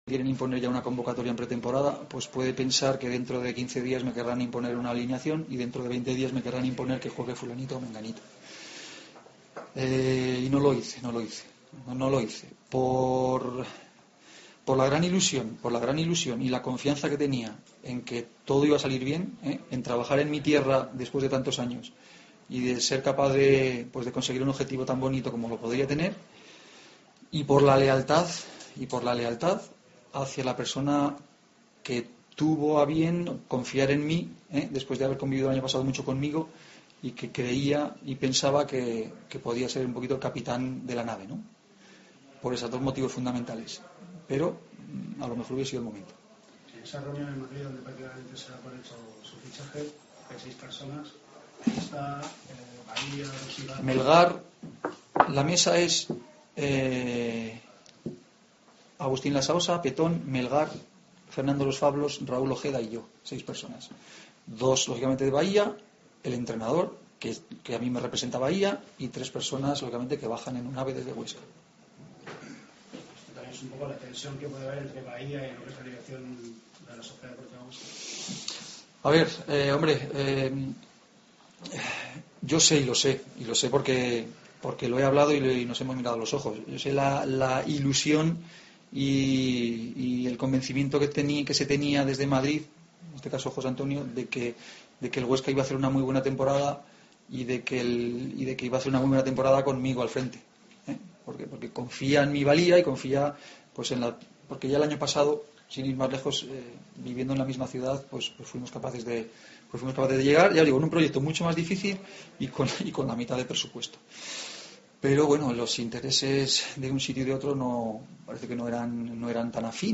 Rueda de prensa de Pablo Alfaro (parte 2/2)